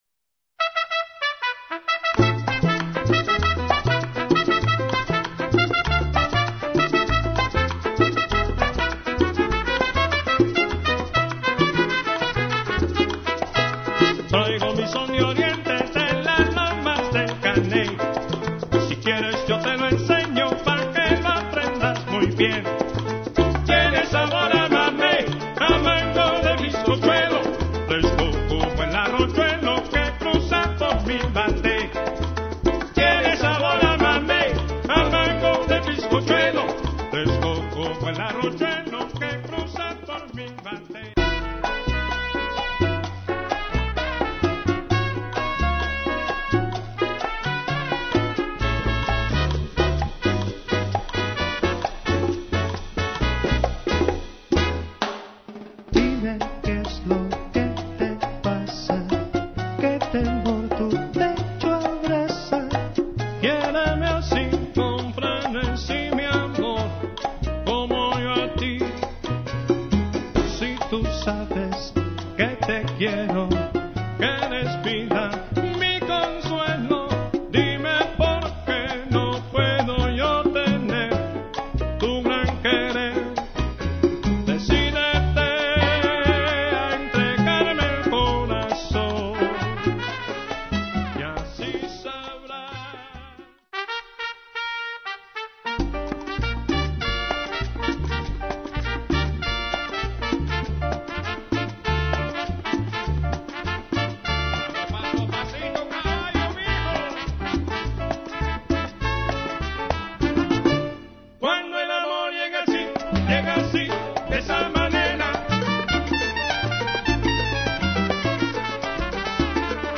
studio demo - a medley